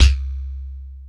LAZERBASC2-R.wav